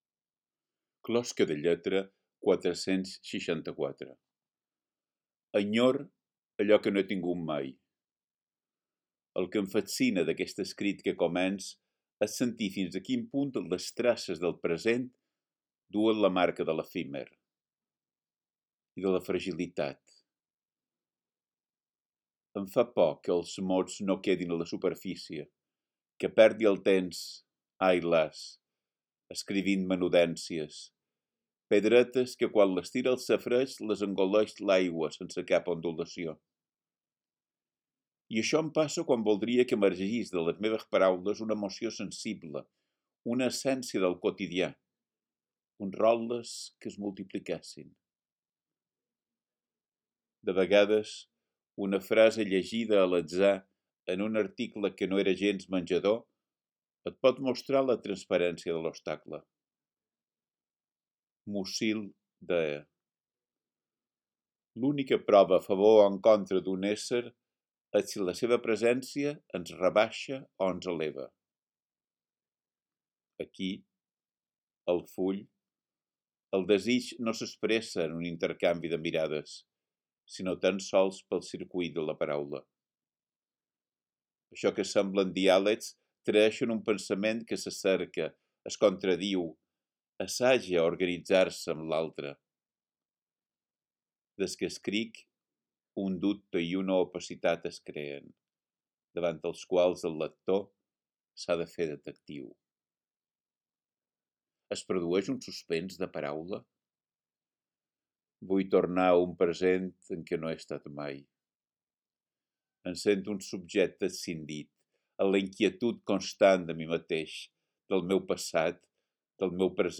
Podeu escoltar ací el text recitat per Biel Mesquida: